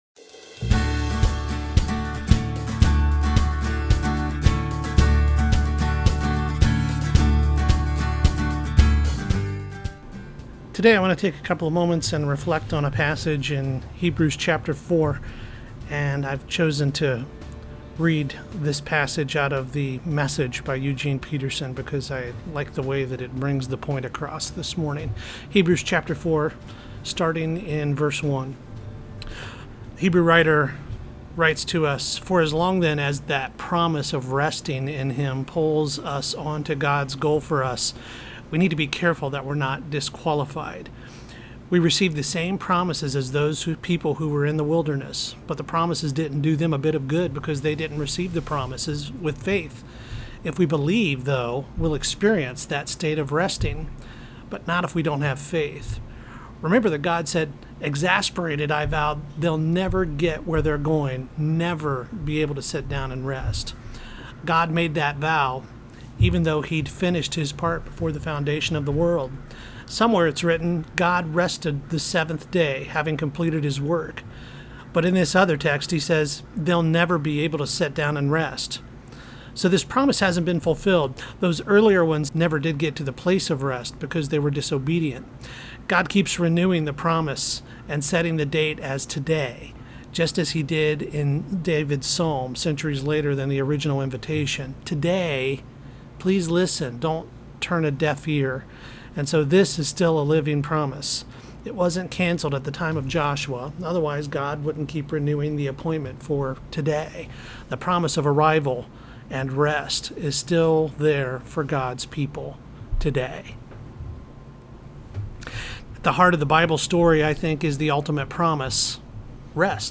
brief meditation from atop a mountain in Tennessee